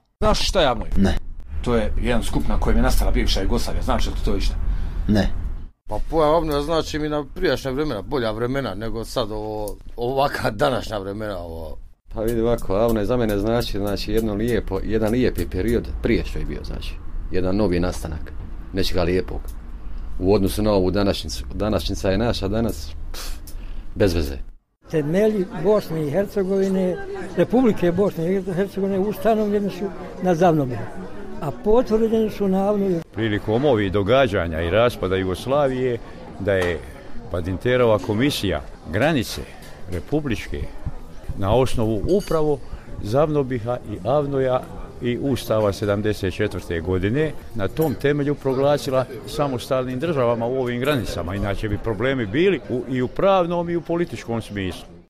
Građani o AVNOJ-u